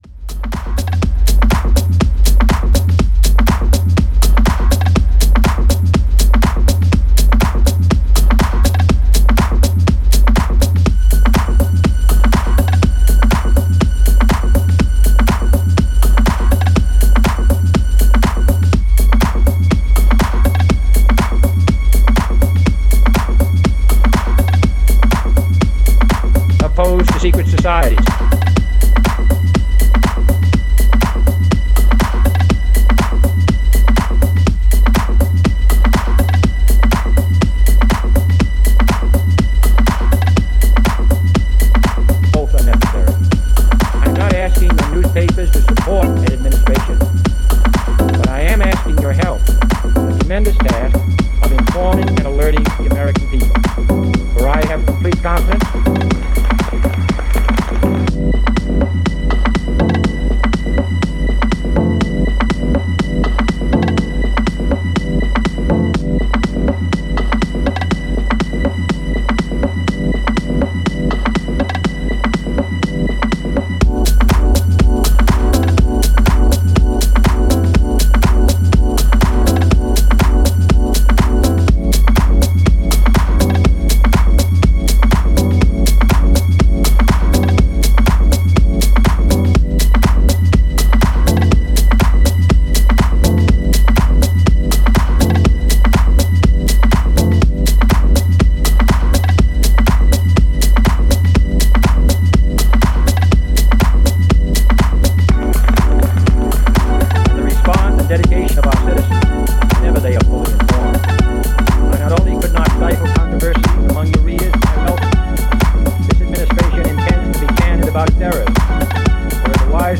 Style: House